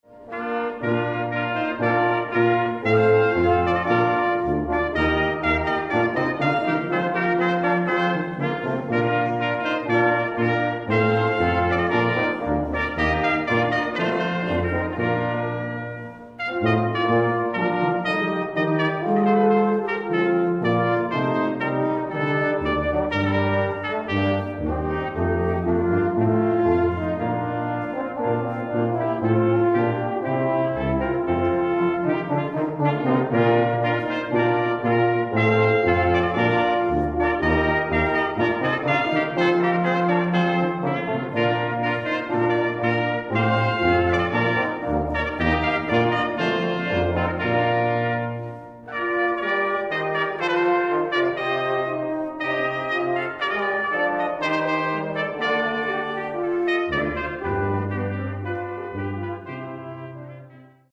Beispiele für kleine Besetzungen aus unseren Konzerten:
2009: 'Prelude aus Te Deum' - Blechbläsergruppe des MV Spöck - Ausschnitt (mp3)